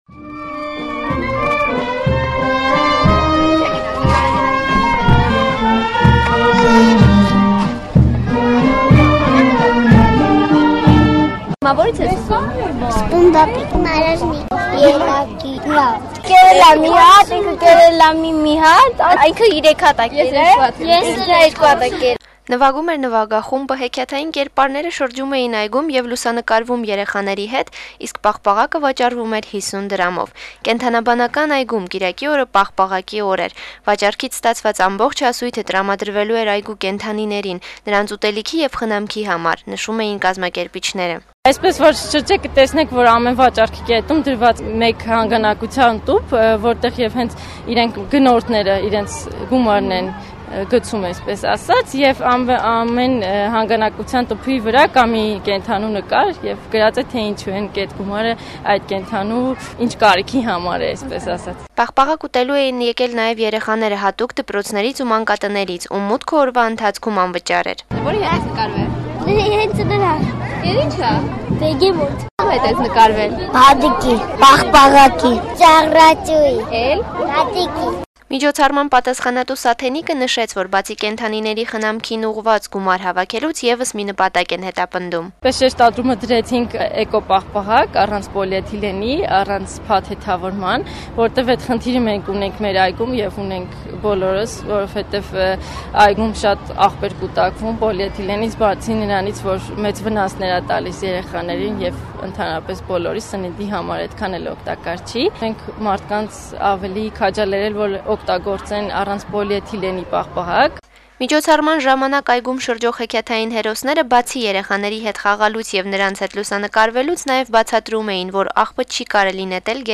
Նվագում էր նվագախումբը, հեքիաթային կերպարները շրջում էին այգում եւ լուսանկարվում երեխաների հետ, իսկ պաղպաղակը վաճառվում էր 50 դրամով: Կենդանաբանական այգում պաղպաղակի օր էր: